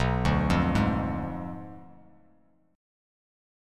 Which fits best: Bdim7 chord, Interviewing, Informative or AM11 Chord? Bdim7 chord